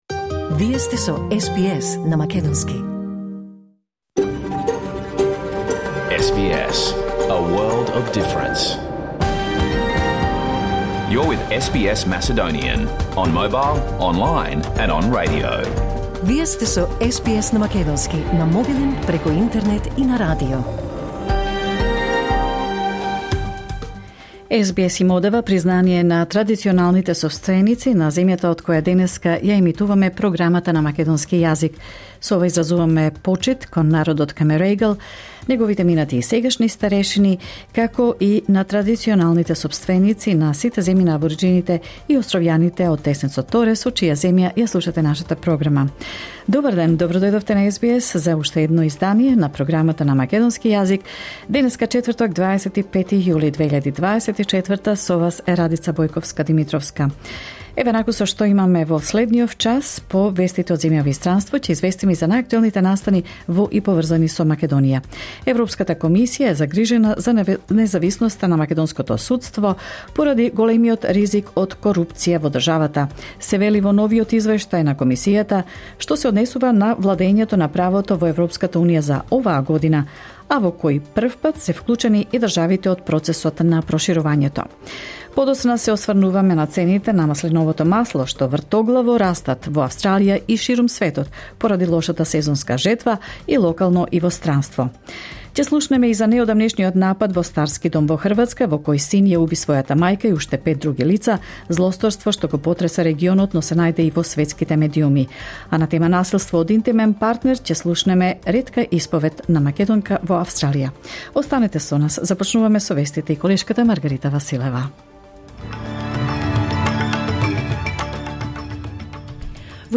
SBS Macedonian Program Live on Air 25 July 2024